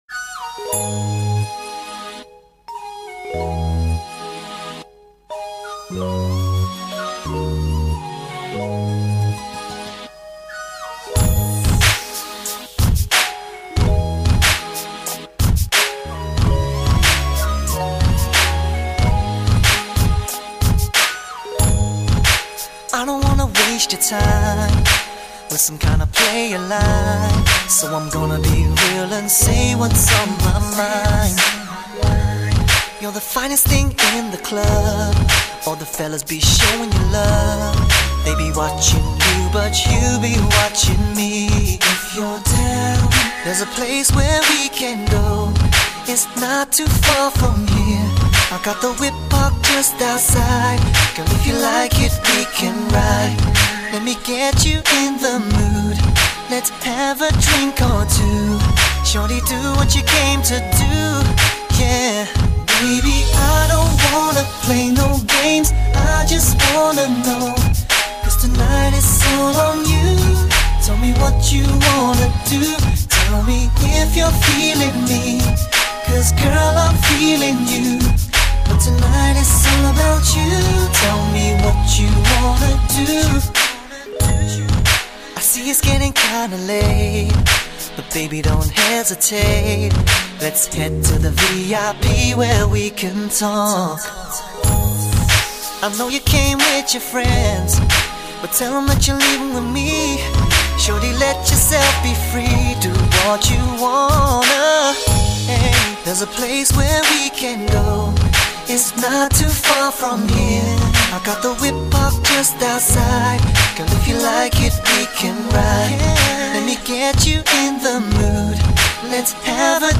量身打造车载音响空间，全方位360度极致环绕3D音效